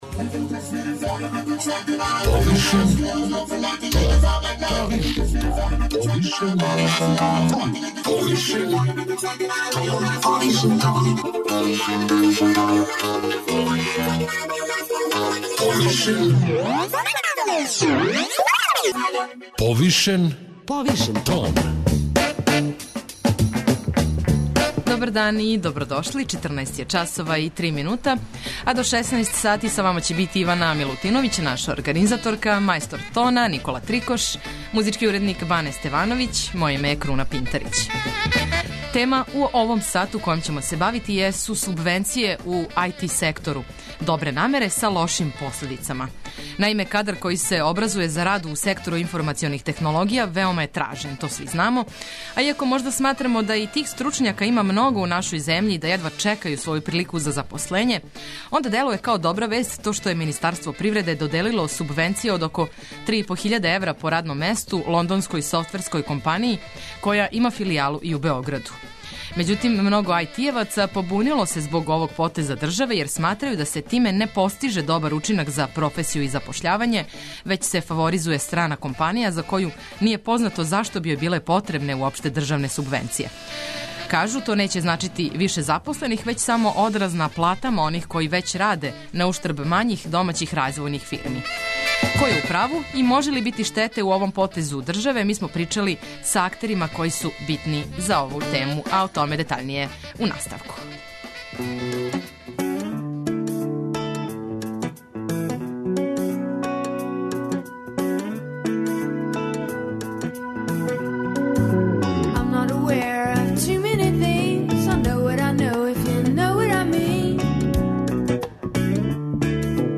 Ко је у праву и може ли бити штете у овом потезу државе, причали смо са актерима битним за ову тему.